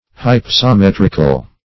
Search Result for " hypsometrical" : The Collaborative International Dictionary of English v.0.48: Hypsometric \Hyp`so*met"ric\, Hypsometrical \Hyp`so*met"ric*al\, a. Of or pertaining to hypsometry.